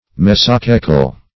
-- Mes`o*cae"cal , a. [1913 Webster]